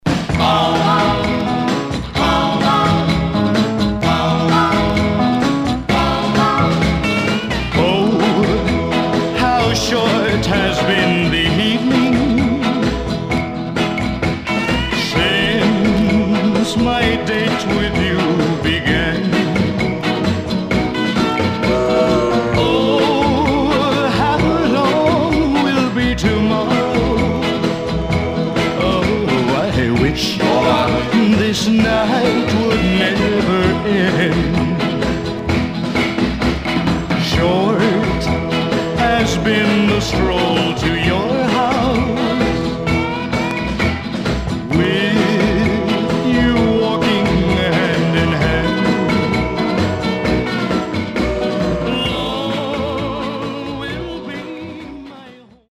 Teen